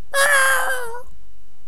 chicken_die1.wav